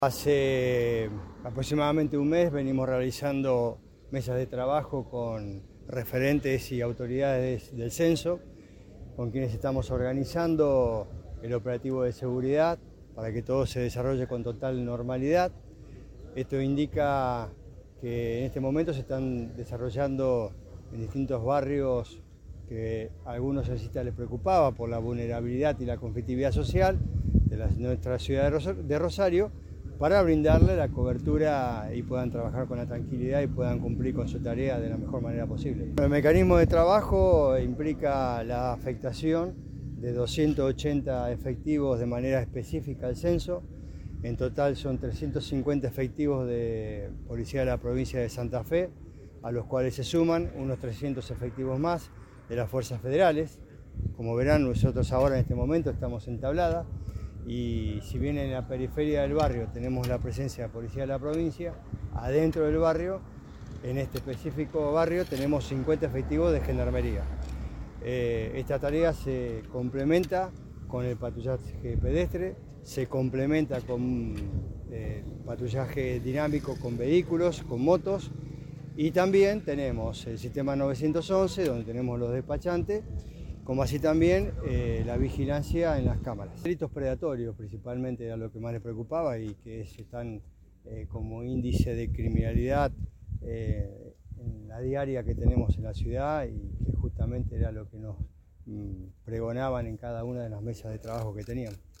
A poco más de cuatro horas de iniciado en Censo presencial, el secretario de Seguridad de la provincia de Santa Fe, Claudio Brilloni, brindó una conferencia de prensa donde informó que unos 650 efectivos de las fuerzas provinciales y federales están trabajando para garantizar la seguridad del operativo que se está llevando adelante con normalidad.
Claudio-Brilloni-Secretario-de-Seguridad.mp3